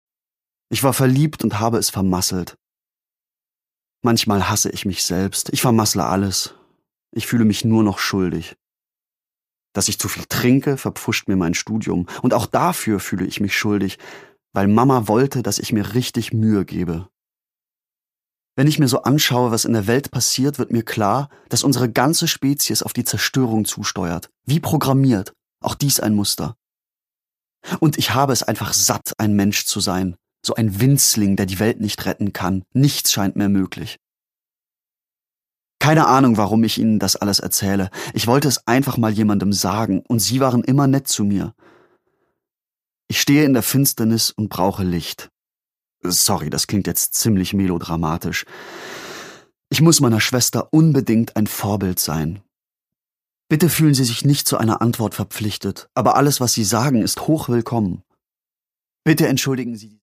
Produkttyp: Hörbuch-Download
Gelesen von: Leslie Malton